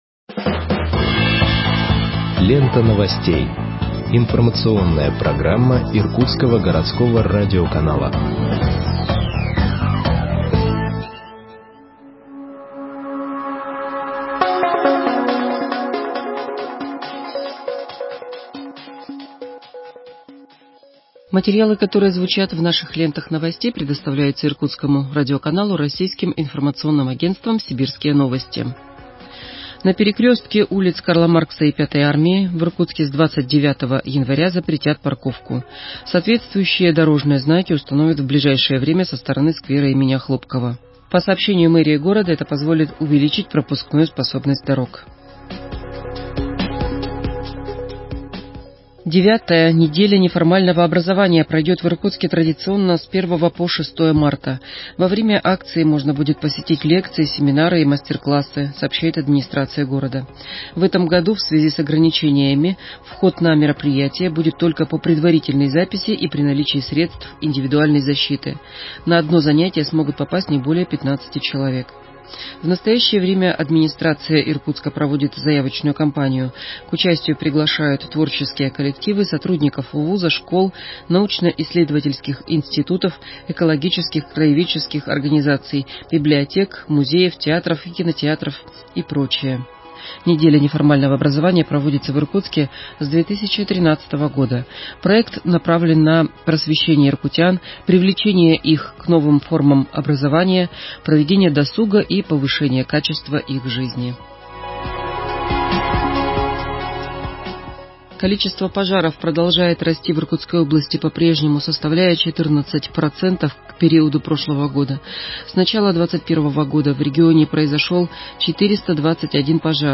Выпуск новостей в подкастах газеты Иркутск от 27.01.2021 № 1